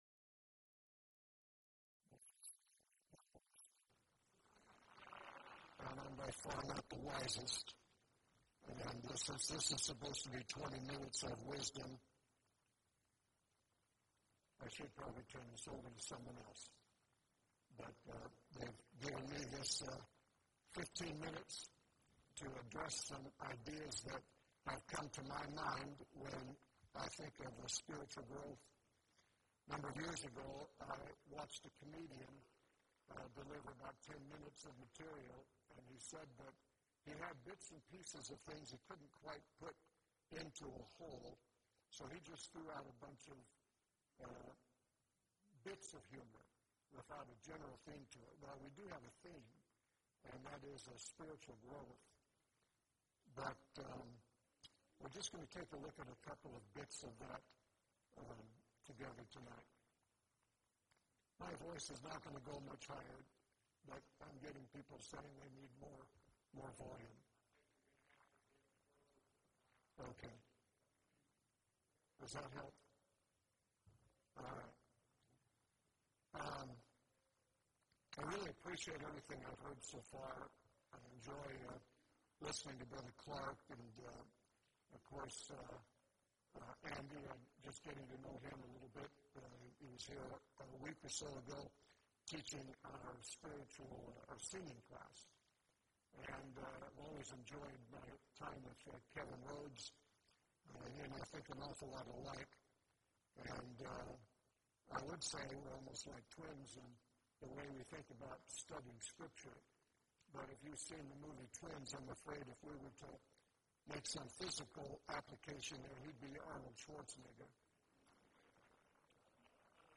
Title: 20 Minutes of Wisdom Speaker(s): Various
Event: 2nd Annual Arise Workshop